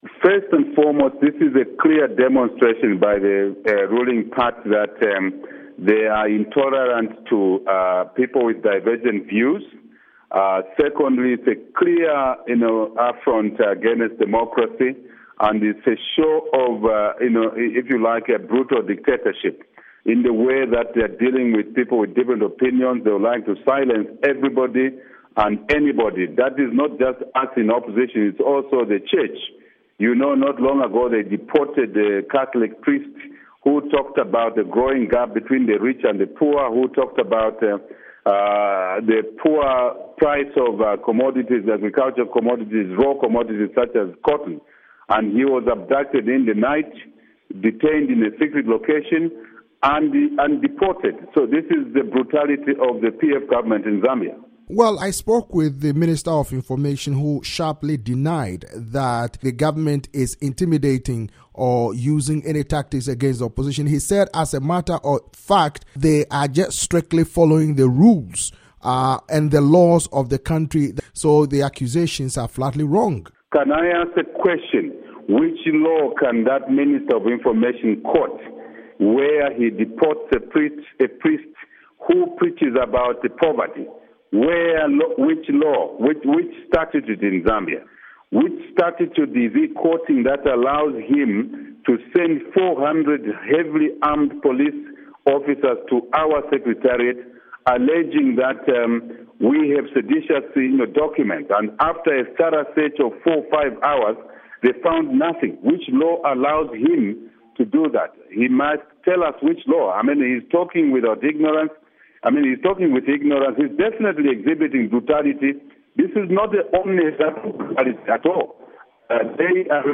interview with Hakainde Hichilema, opposition UPND leader